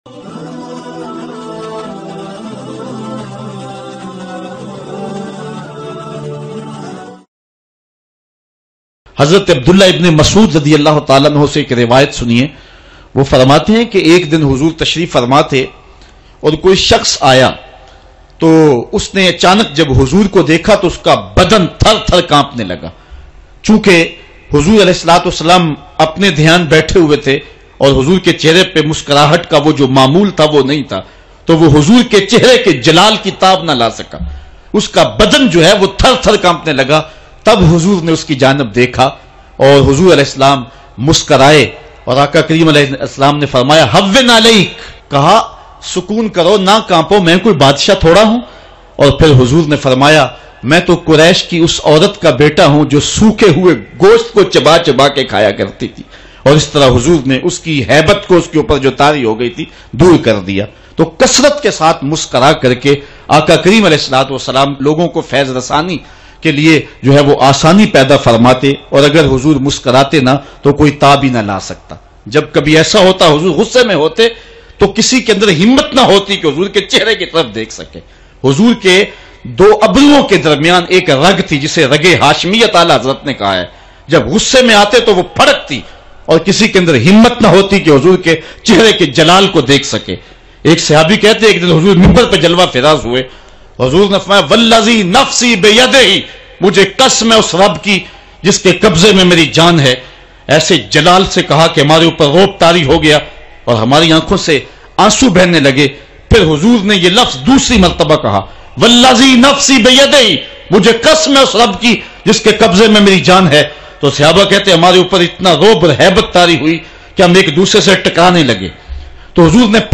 Hazoor SAW K Cheray Par Jalal k Asaar Bayan mp3